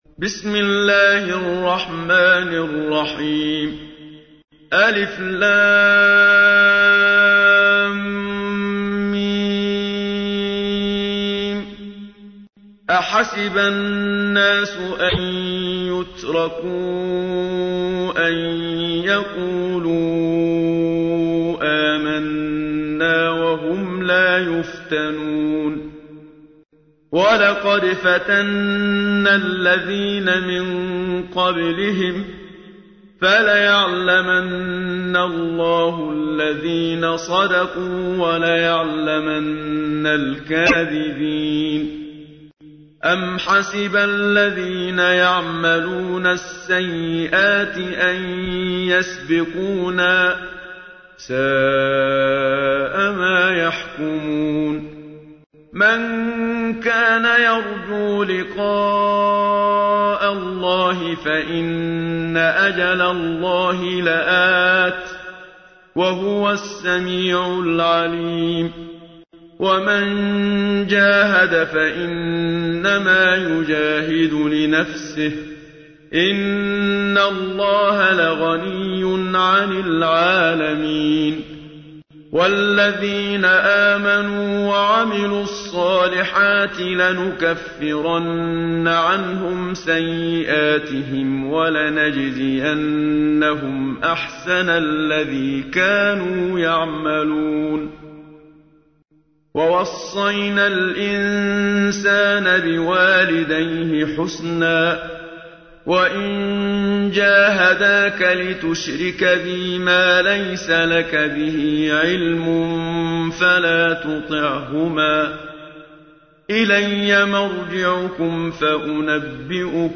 تحميل : 29. سورة العنكبوت / القارئ محمد صديق المنشاوي / القرآن الكريم / موقع يا حسين